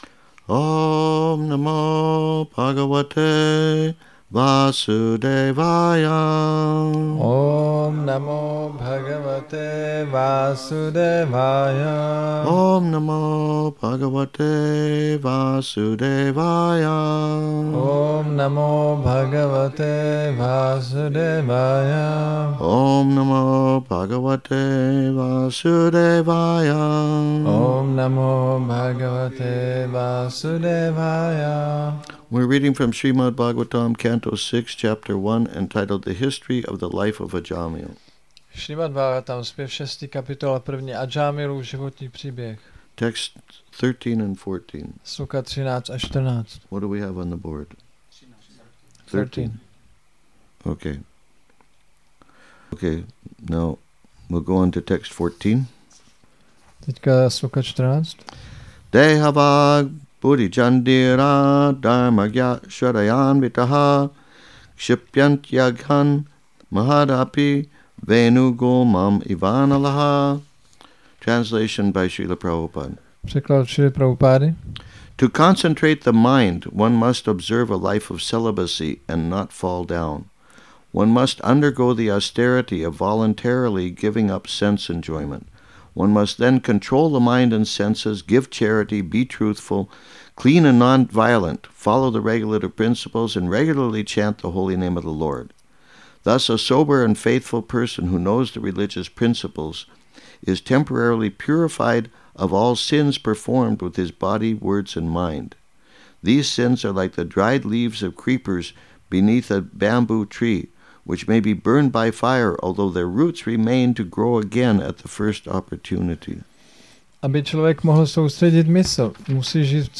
Přednáška SB-6.1.13-14 – Šrí Šrí Nitái Navadvípačandra mandir